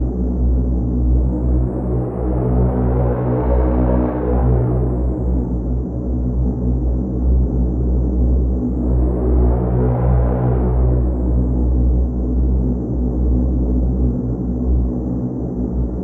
ambience03-old1.wav